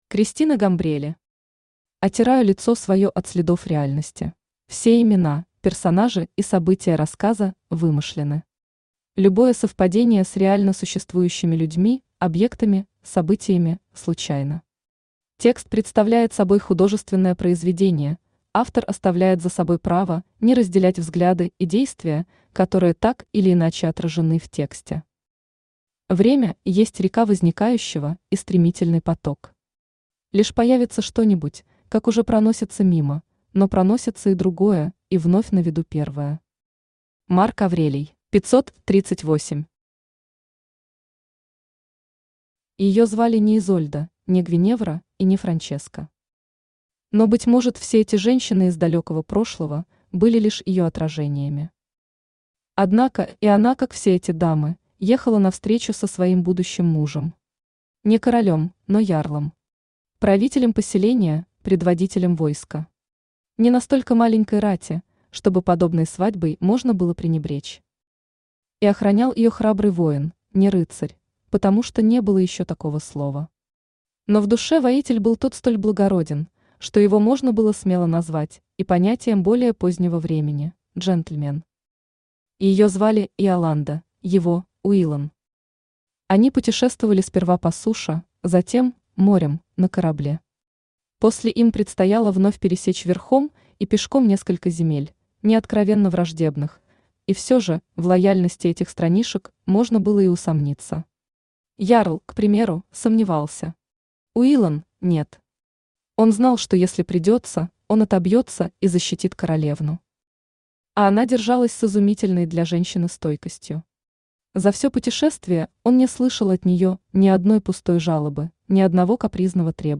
Аудиокнига Отираю лицо свое от следов реальности | Библиотека аудиокниг
Aудиокнига Отираю лицо свое от следов реальности Автор Кристина Гамбрелли Читает аудиокнигу Авточтец ЛитРес.